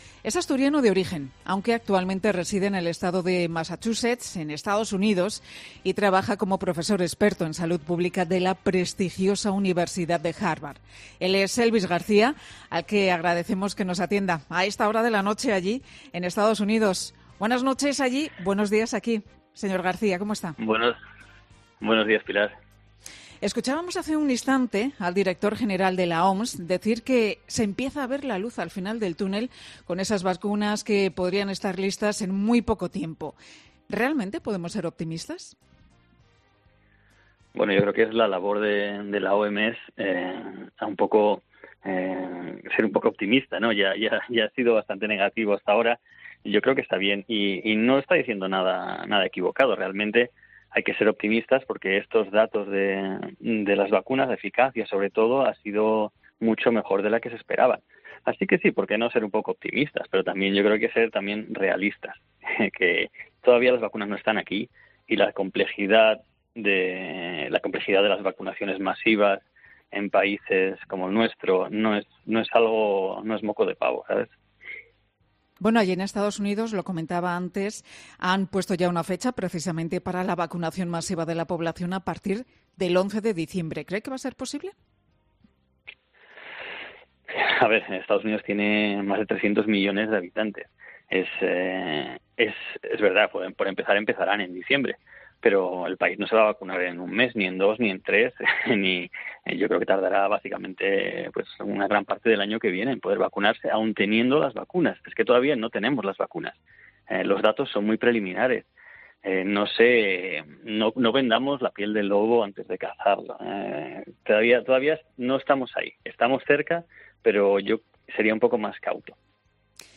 Con Carlos Herrera